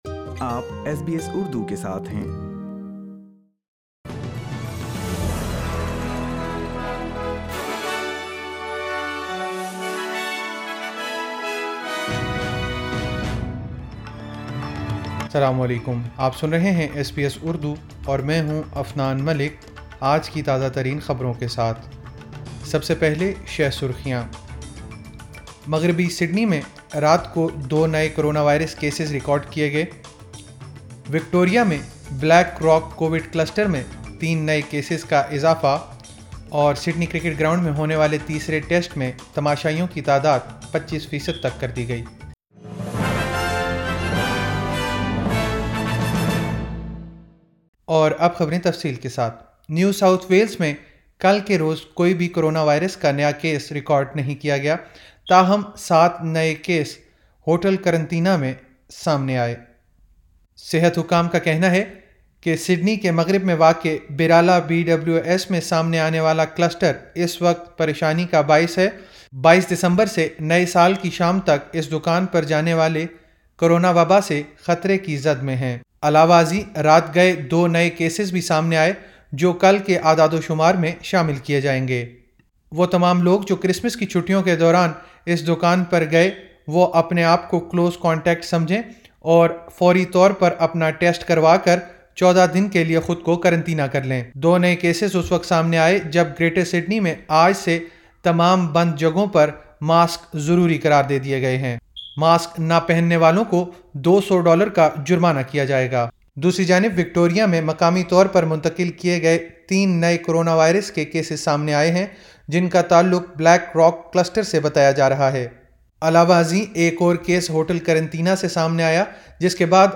ایس بی ایس اردو خبریں 04 جنوری 2021